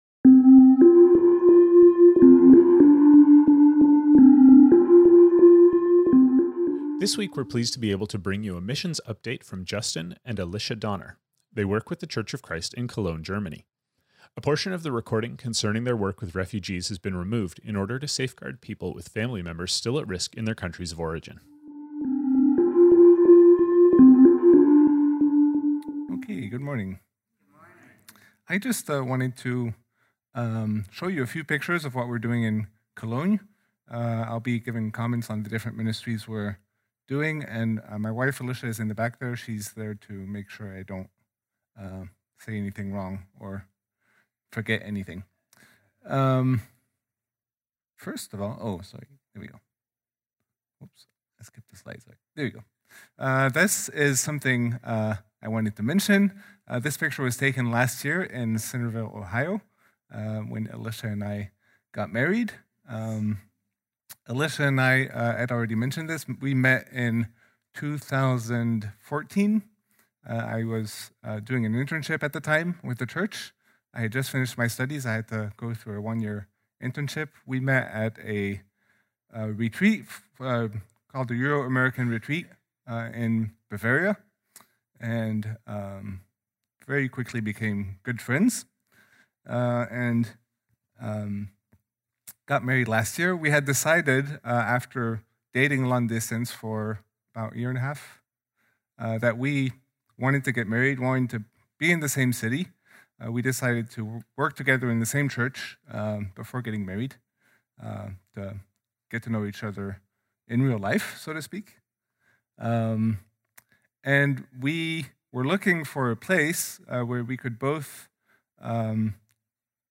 A portion of the recording concerning their work with refugees has been edited out in order to safeguard those with family members still at risk in their countries of origin.